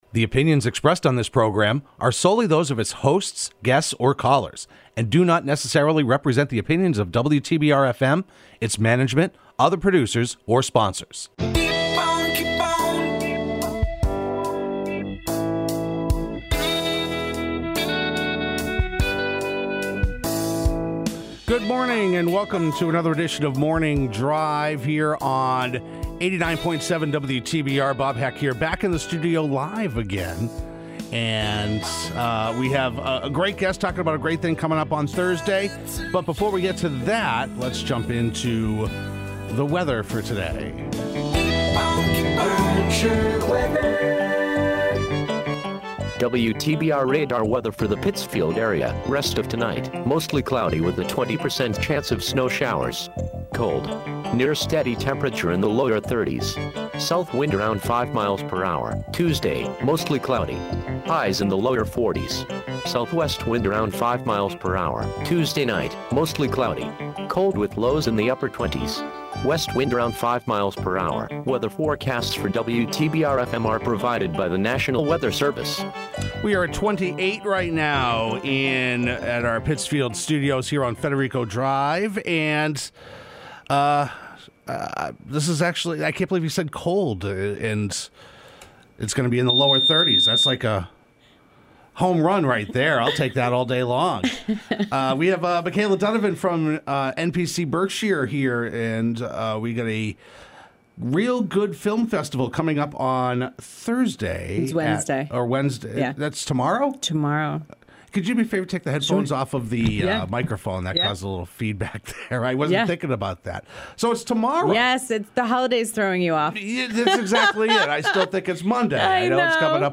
Today host